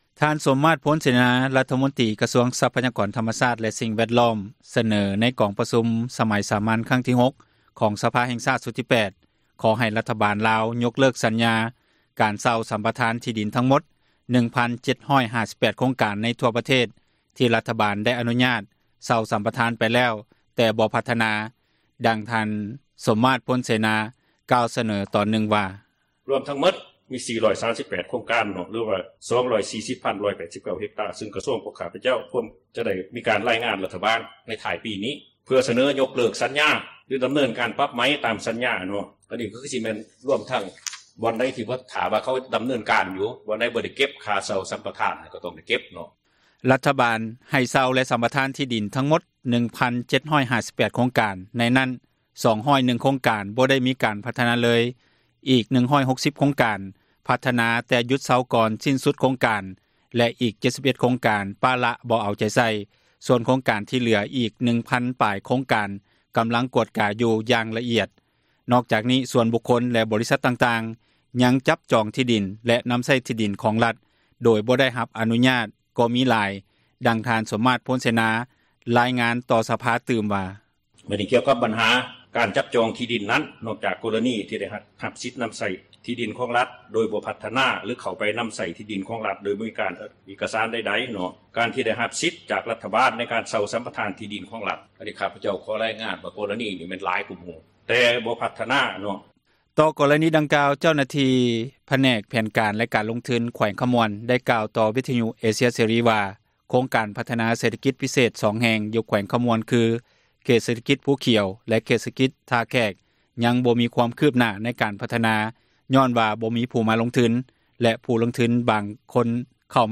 ທ່ານ ສົມມາດ ພົນເສນາ ຣັຖມົນຕຣີ ກະຊວງຊັພກອນ ທັມມະຊາດ ແລະສິ່ງແວດລ້ອມ ສເນີຕໍ່ກອງປະຊຸມສະໄໝສາມັນຄັ້ງທີ 6 ຂອງ ສະພາແຫ່ງຊາດ ຊຸດທີ 8 ຂໍໃຫ້ຣັຖບານ ຍົກເລີກສັນຍາ ເຊົ່າ-ສັມປະທານ ທີ່ດິນທັງໝົດ 1,758 ໂຄງການທົ່ວປະເທສ ທີ່ຣັຖບານໄດ້ ອະນຸຍາດ ເຊົ່າ-ສັມປະທານ ໄປແລ້ວແຕ່ບໍ່ພັທນາ.